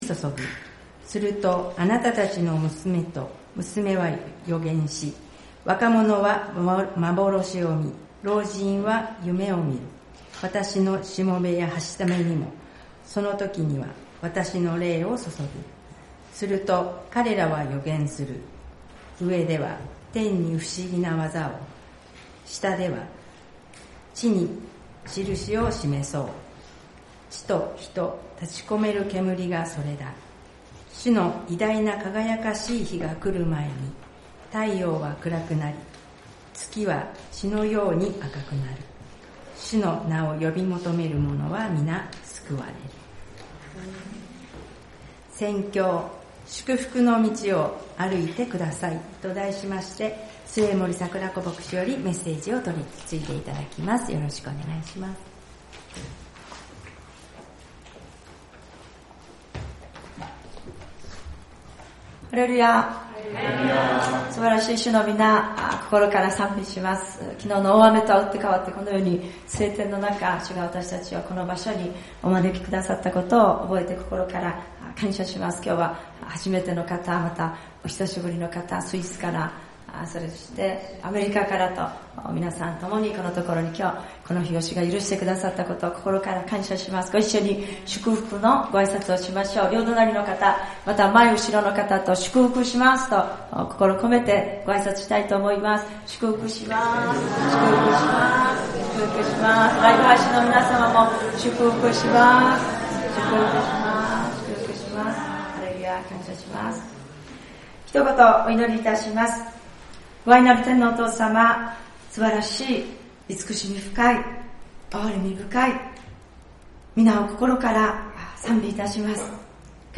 聖日礼拝「祝福の道を歩いてください